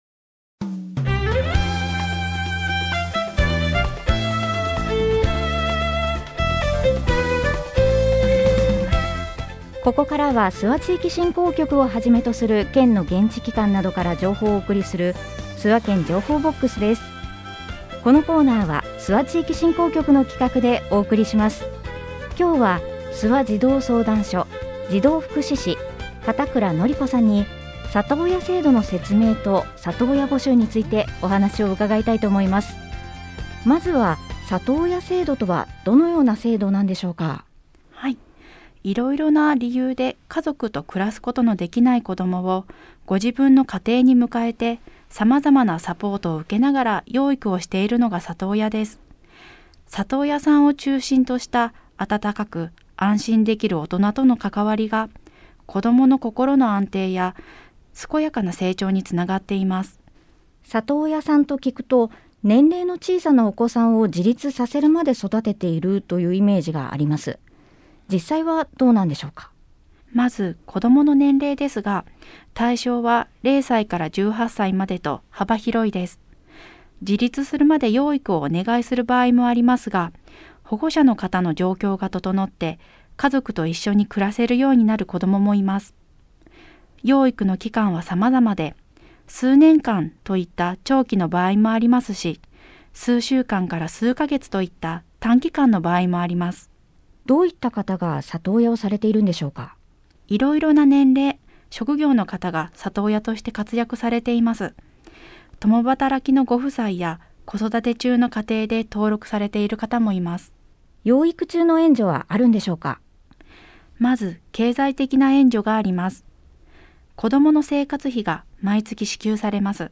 コミュニティエフエムを活用した地域情報の発信